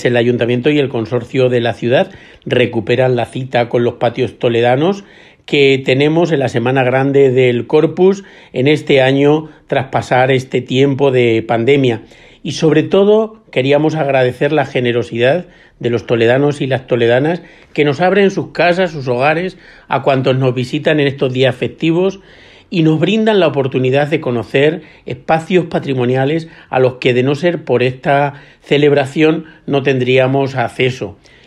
AUDIOS. Teo García, concejal de Cultura y Patrimonio Histórico